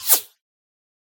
whine_2.ogg